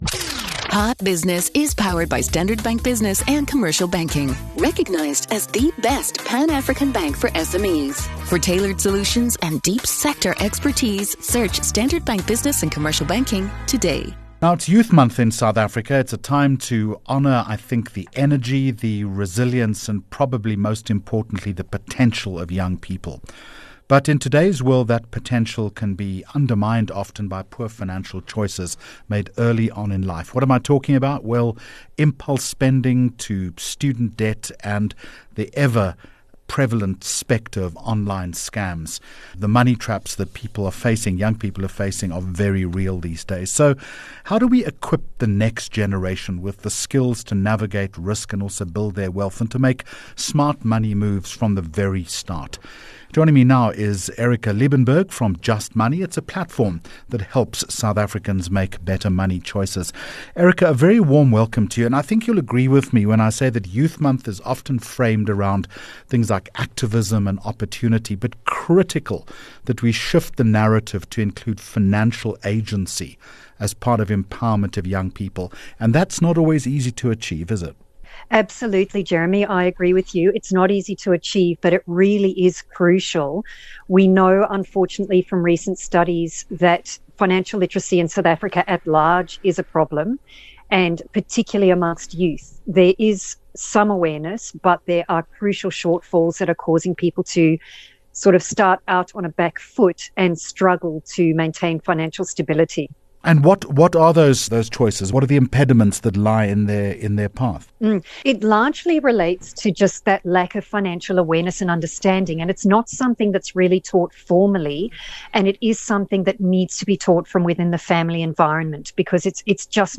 9 Jun Hot Business Interview